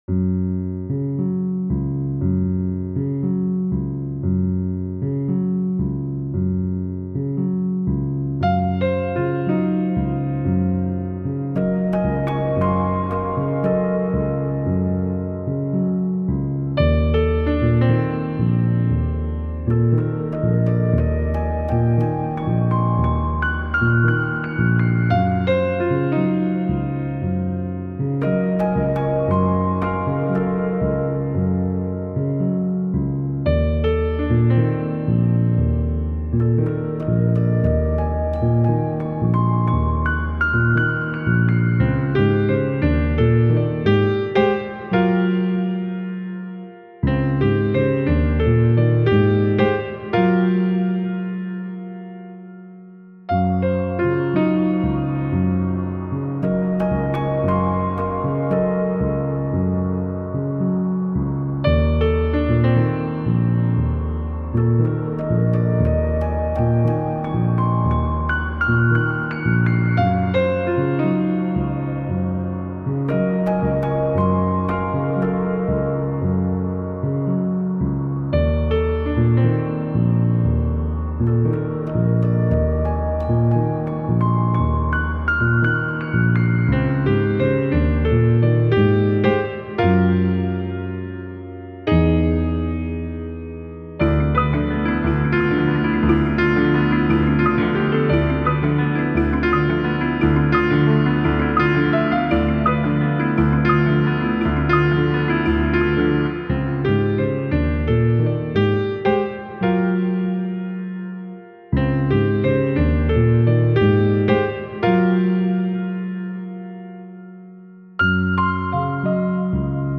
Treated pianos echo across the magical water-filled arena.